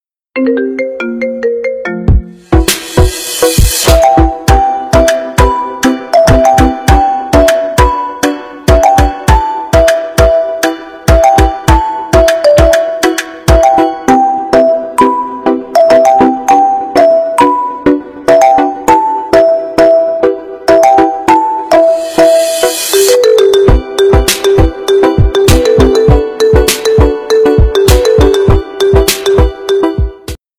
• Качество: 320, Stereo
громкие
спокойные